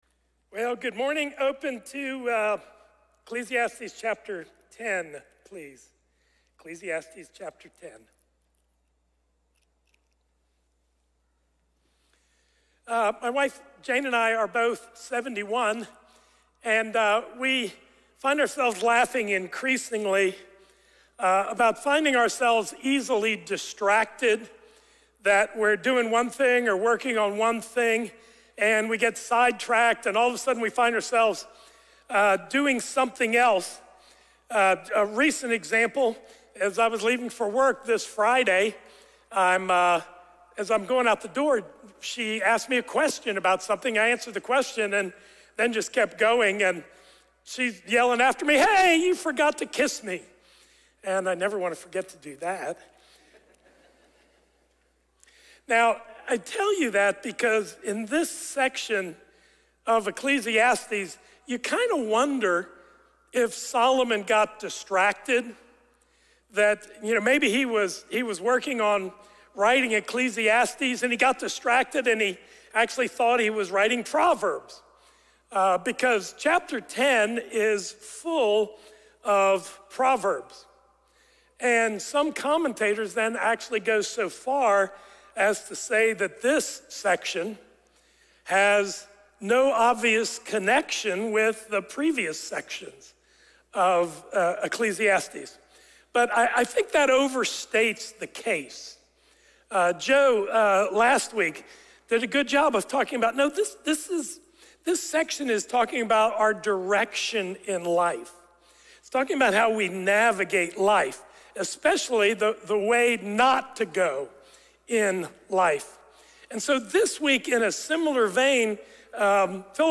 Crossway Community Church